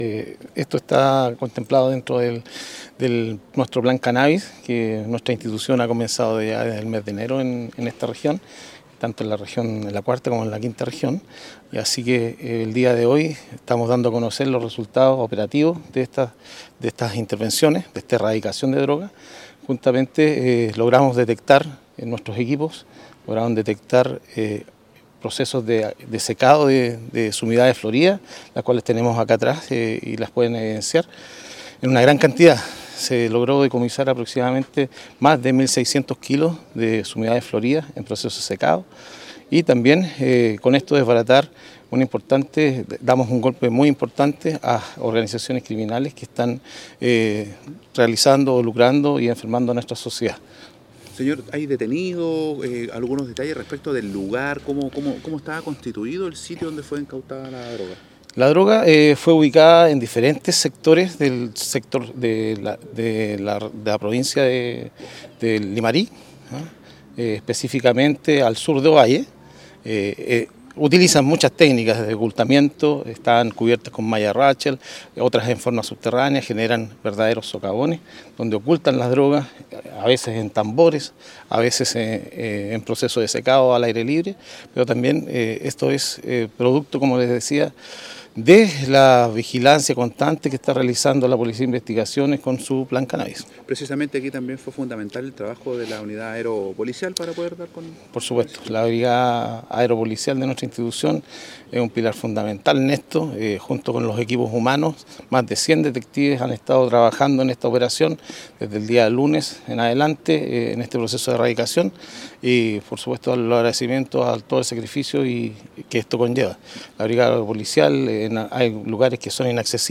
Esta multimillonaria incautación y todas sus evidencias fueron exhibidas a las autoridades y medios de comunicación en el Estadio Municipal Diaguita de Ovalle.
el-prefecto-inspector-Ernesto-Leon-jefe-de-la-Region-Policial-de-Coquimbo.mp3